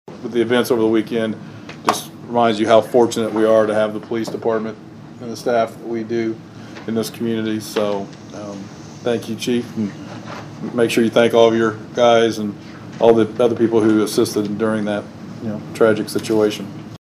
During Monday’s Vandalia City Council meeting, Mayor Knebel thanked the Vandalia Police Department for their work in the incident.